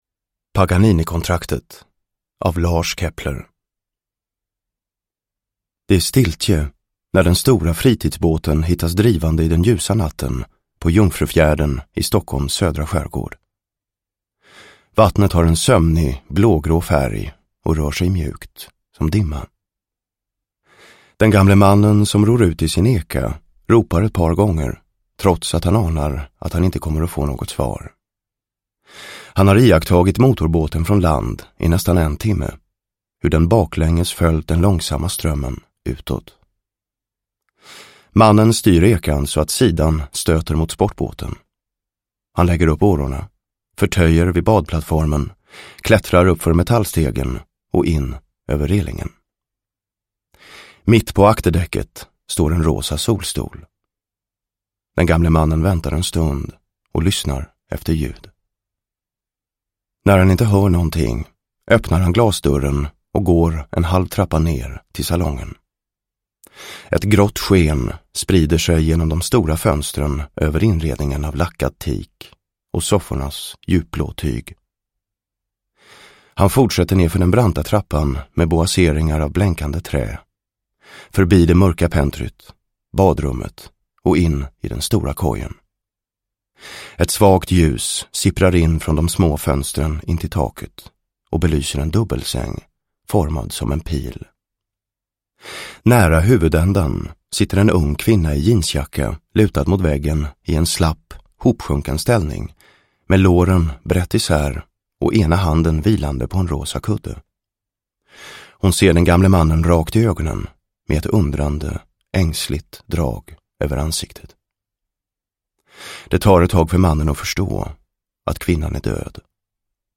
Paganinikontraktet / Ljudbok